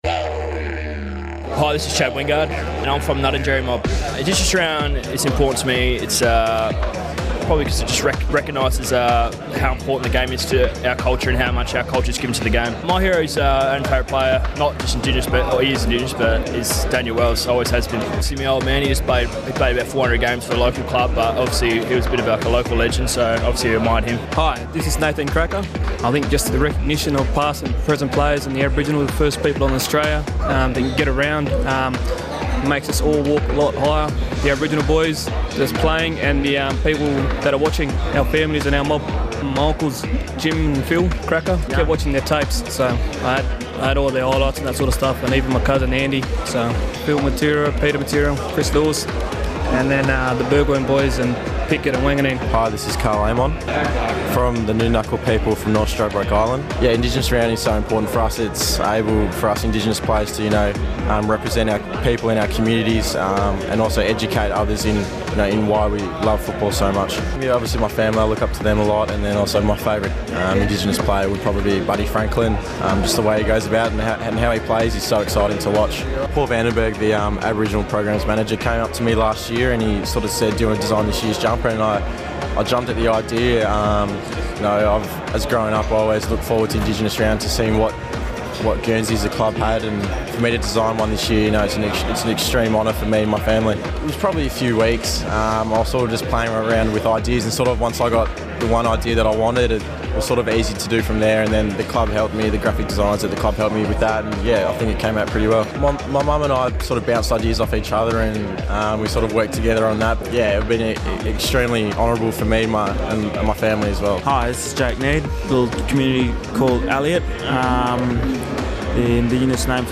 The five Indigenous players who represented Port Adelaide during the Sir Doug Nicholls Indigenous Round talk to ABC Grandstand about what it means.